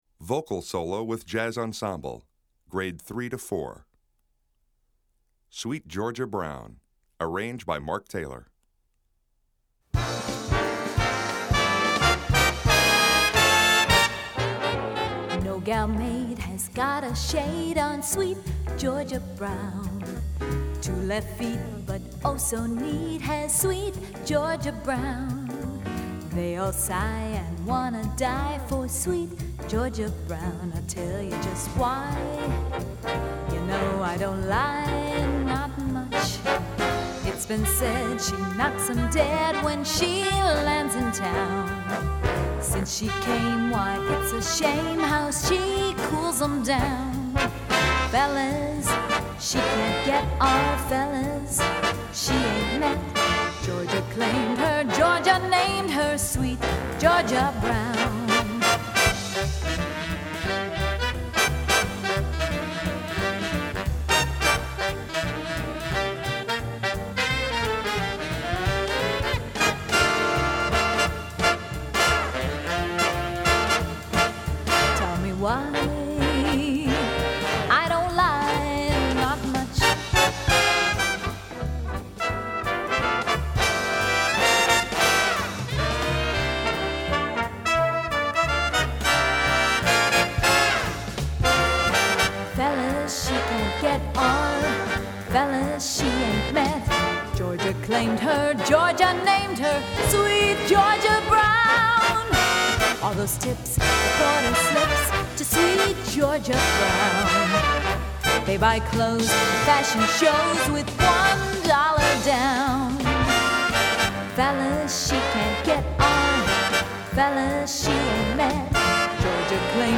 Key: B-flat